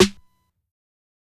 SNARE_DOORDIE.wav